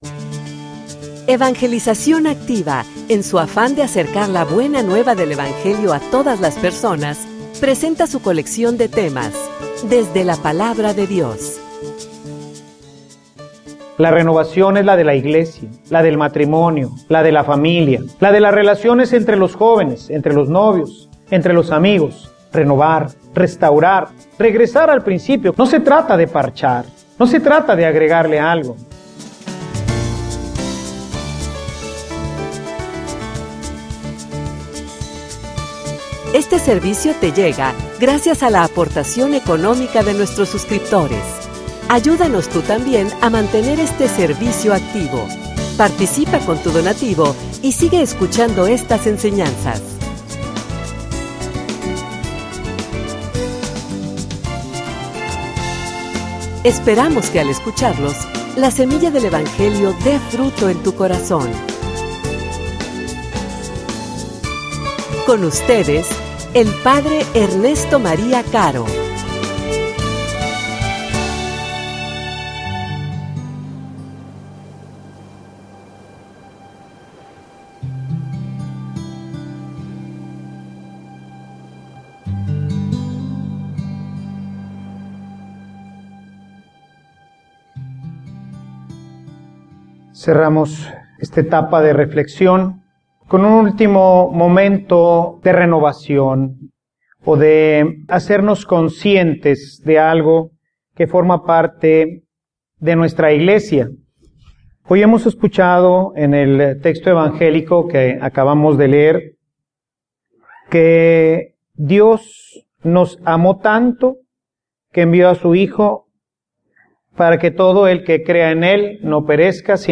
homilia_Renueva_mi_Iglesia.mp3